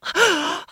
gasp.wav